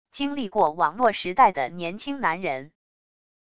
经历过网络时代的年轻男人wav音频